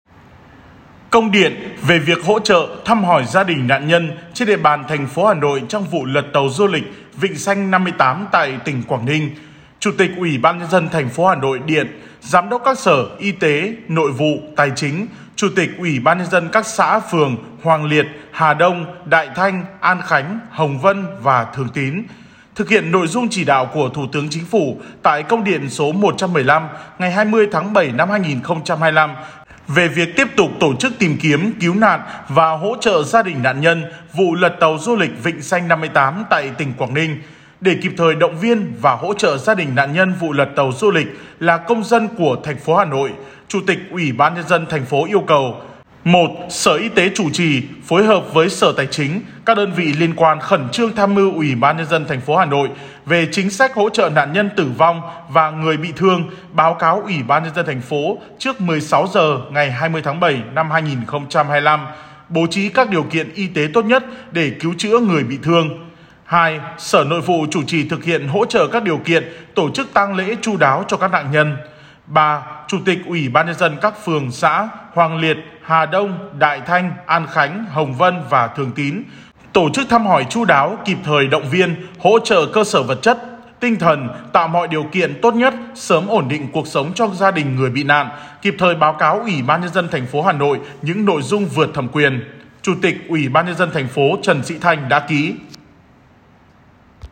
File phát thanh Công điện của UBND thành phố Hà Nội về hỗ trợ các gia đình nạn nhân vụ lật tàu ở Quảng Ninh